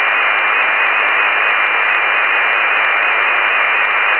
91 Кб 18.11.2009 02:15 Непонятный сигнал!